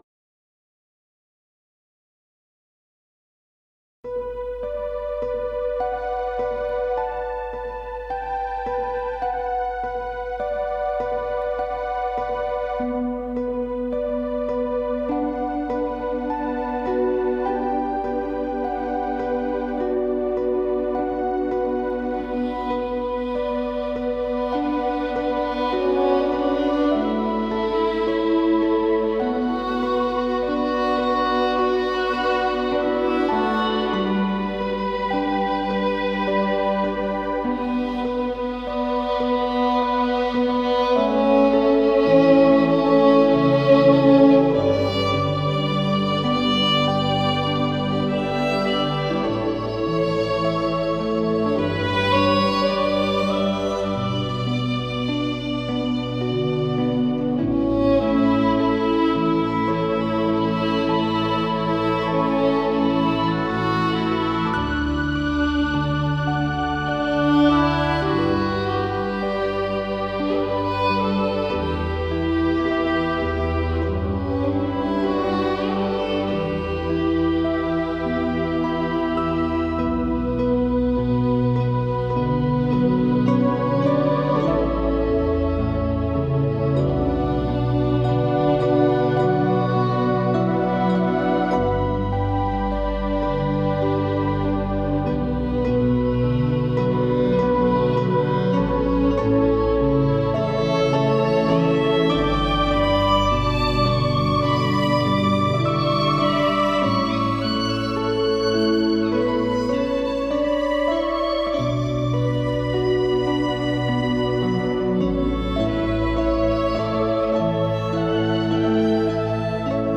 the violin and the guitar